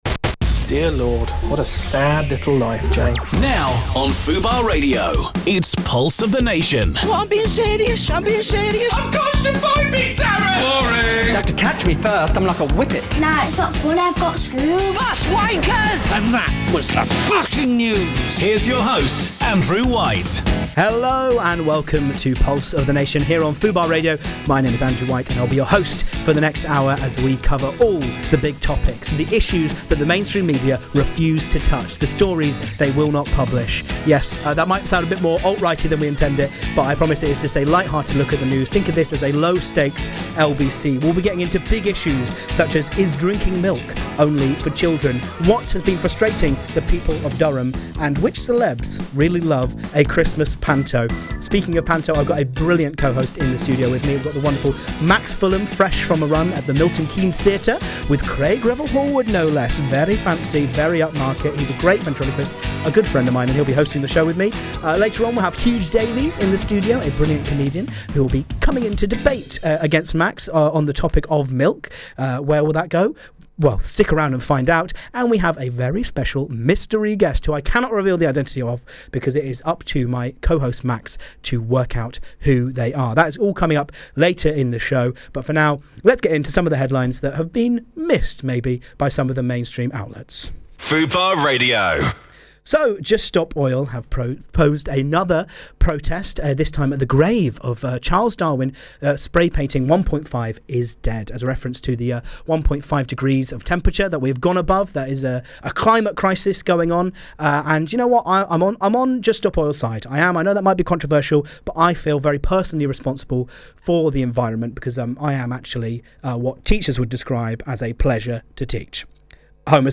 Pulse Of The Nation is live on FUBAR Radio on Wednesdays 2pm-3pm.